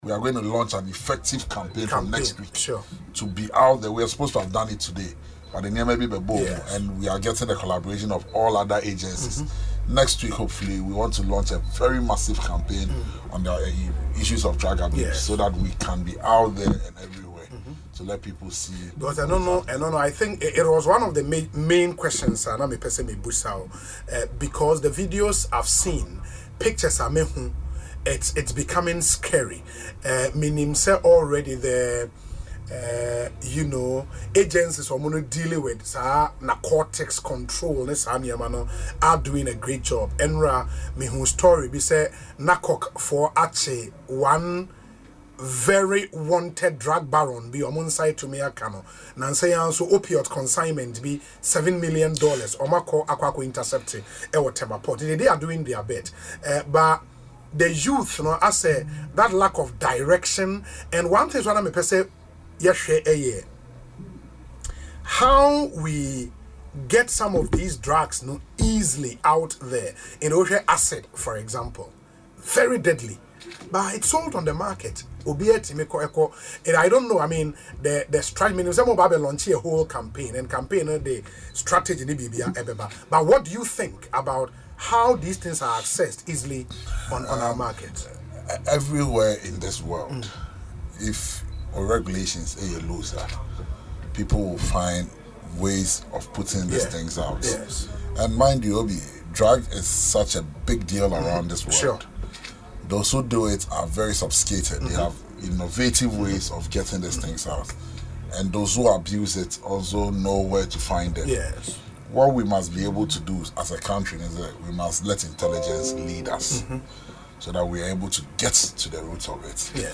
Speaking in an interview on Asempa FM’s Ekosii Sen show, Mr. Opare urged the security agencies to be more vigilant and proactive.